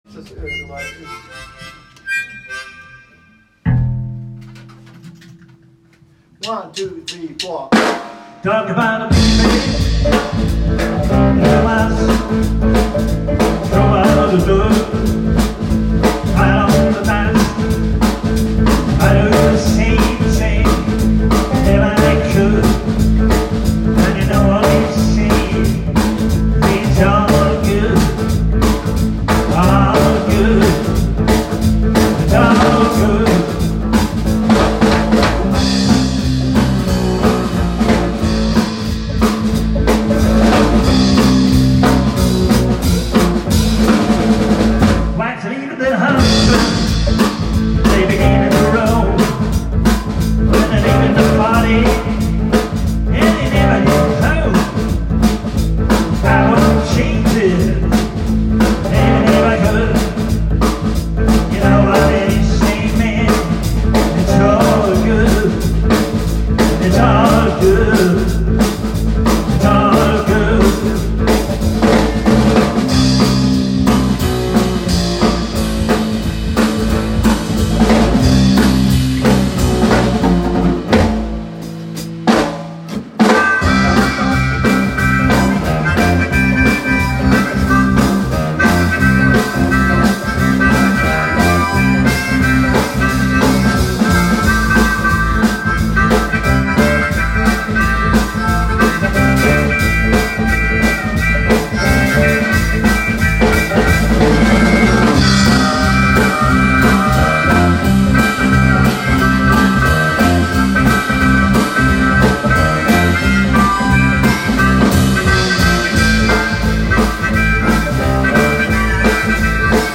Einiges davon durchaus tanzbar.